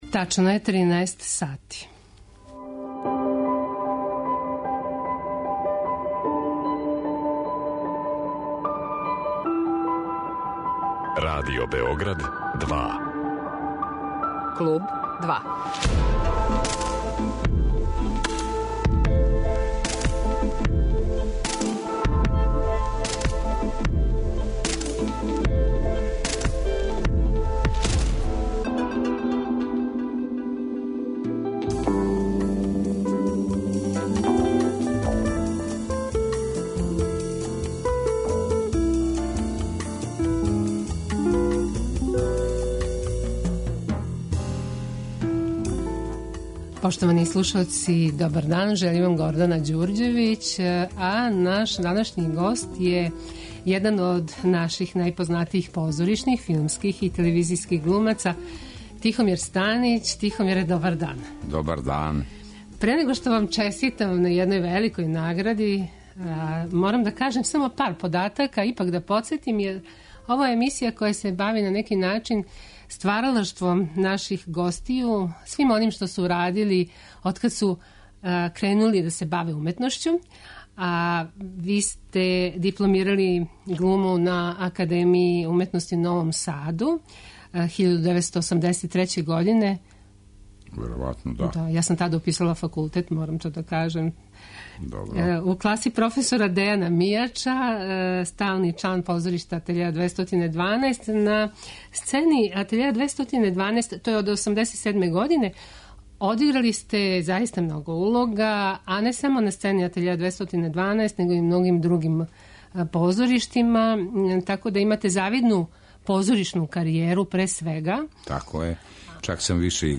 Гост Клуба 2 један је од наших најпознатијих позоришних, филмских и телевизијских глумаца Тихомир Станић.